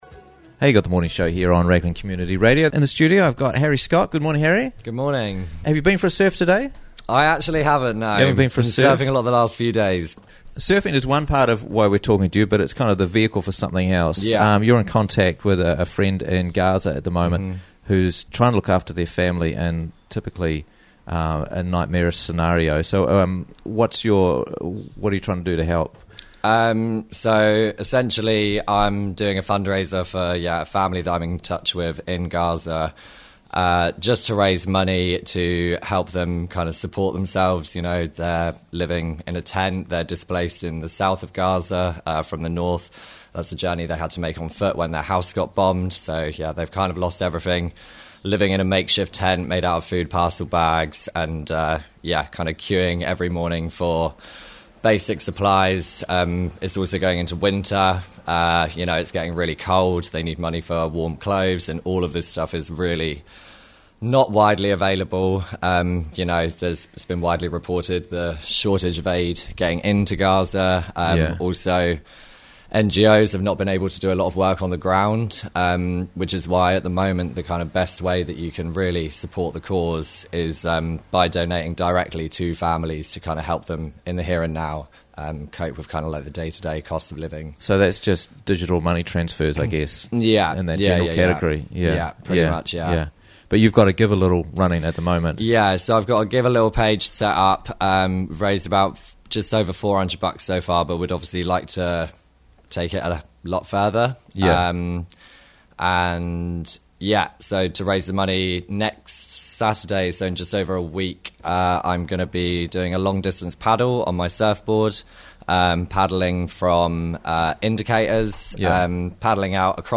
Paddle the Points for Palestine - Interviews from the Raglan Morning Show